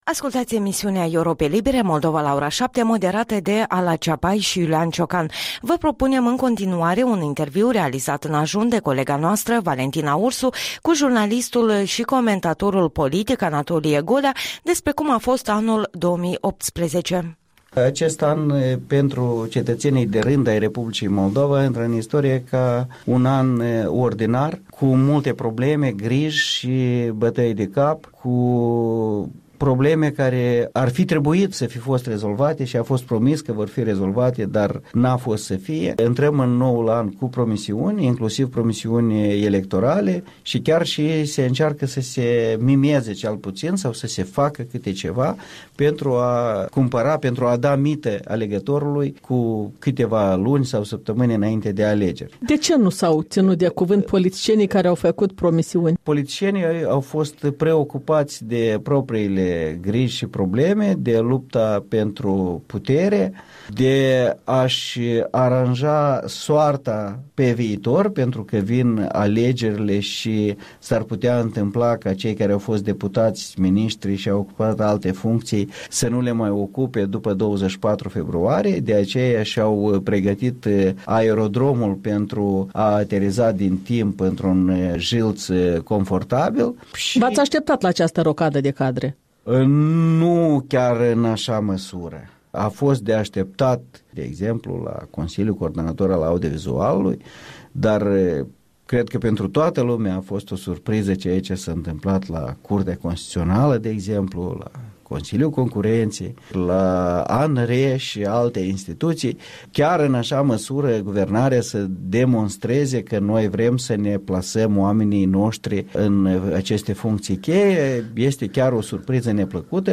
Interviul dimineții cu jurnalistul și comentatorul politic despre societatea moldovenească în anul 2018.